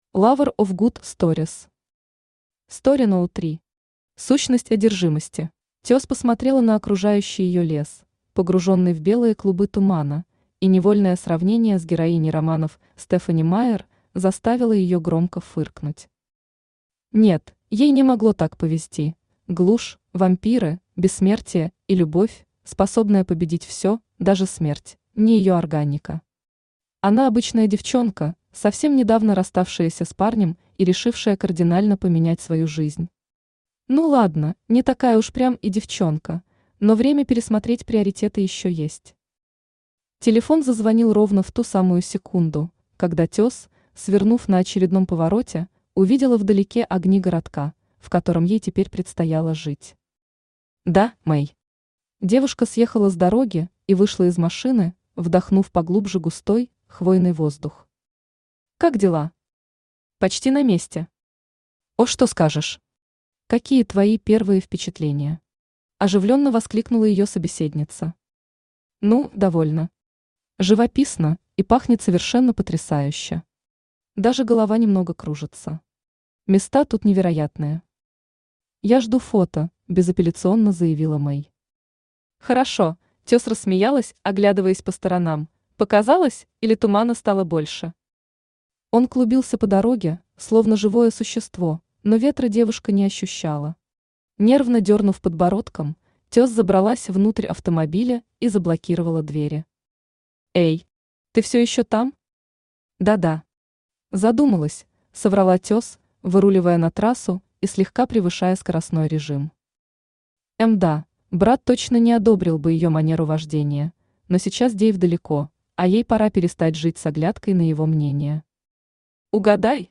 Аудиокнига Story № 3. Сущность одержимости | Библиотека аудиокниг
Сущность одержимости Автор Lover of good stories Читает аудиокнигу Авточтец ЛитРес.